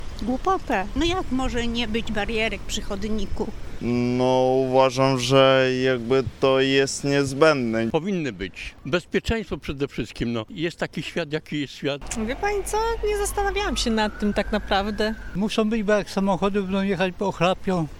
Mieszkańcy Stargardu wyrażają swoje obawy: